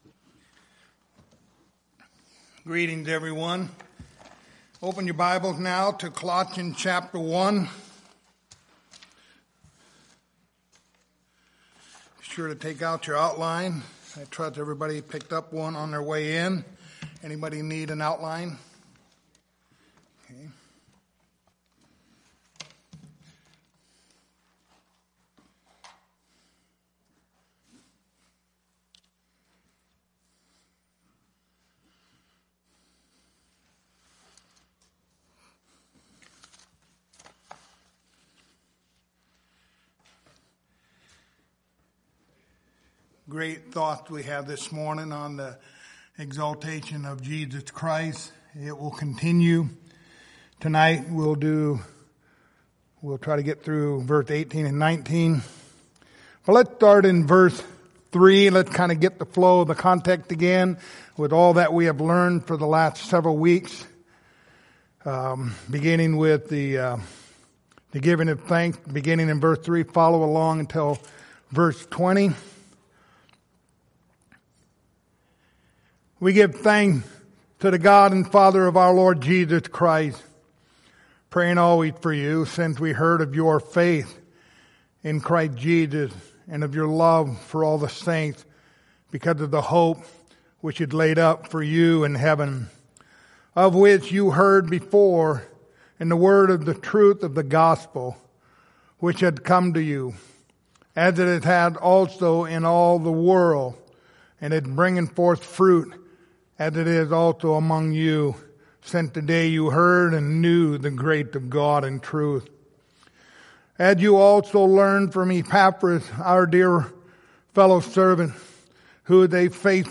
Lord's Supper Passage: Colossians 1:18-20 Service Type: Lord's Supper Topics